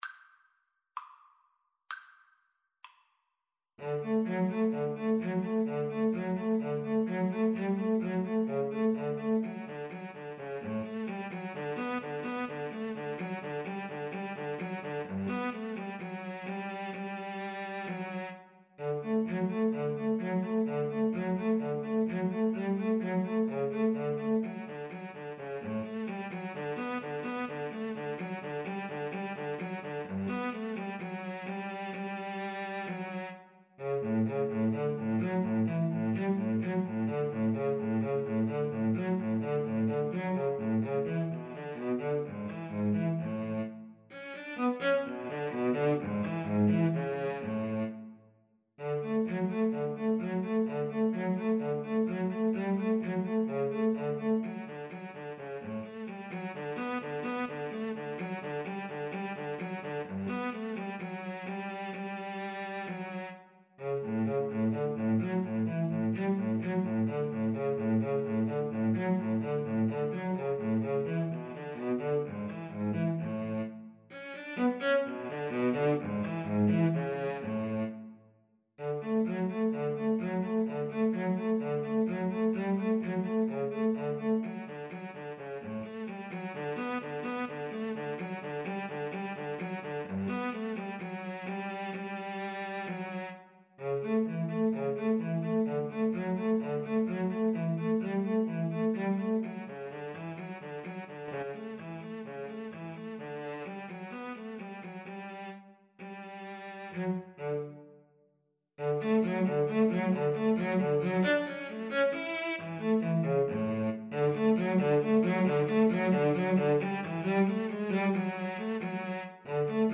Andante sostenuto = 64
2/4 (View more 2/4 Music)
D major (Sounding Pitch) (View more D major Music for Violin-Cello Duet )
Violin-Cello Duet  (View more Easy Violin-Cello Duet Music)
Classical (View more Classical Violin-Cello Duet Music)